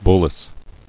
[bul·lace]